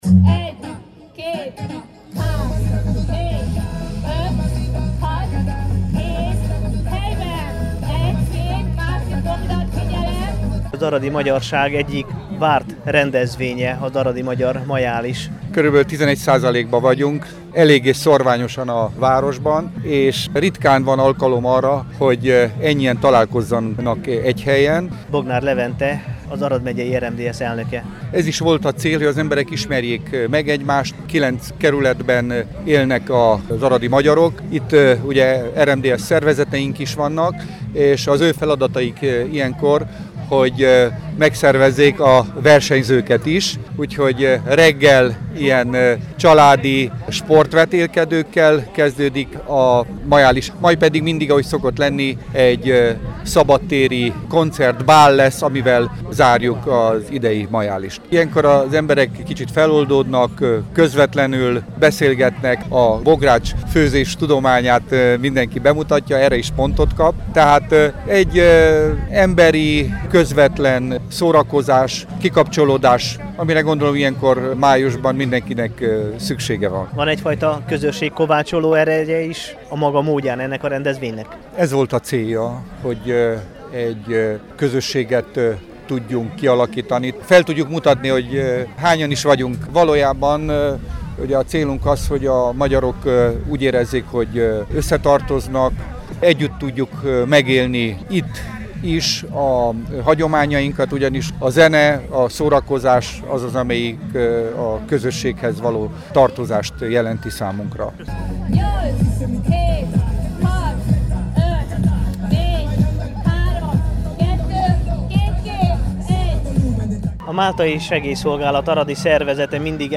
hogy a „bemelegítésről" riportot készítsen a Temesvári Rádió számára.
Aradi_Magyar_Majalis_2014_kezdes.mp3